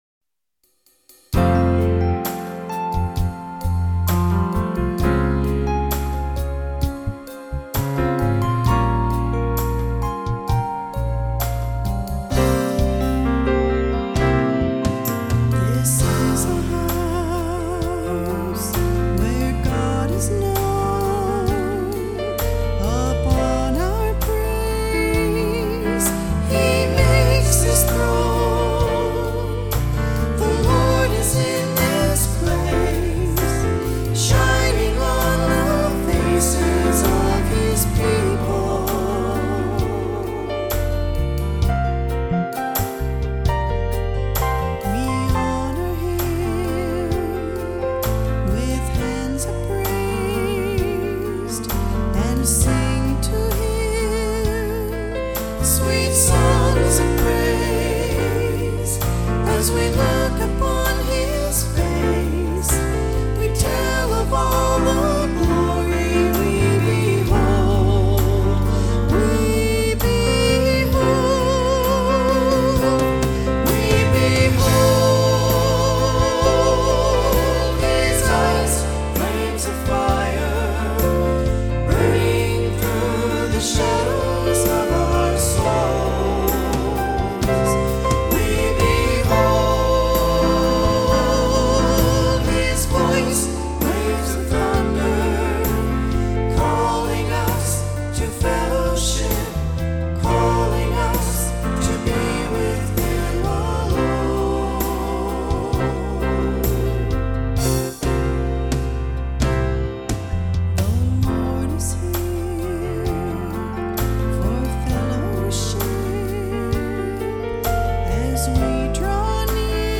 keyboard
sang background vocals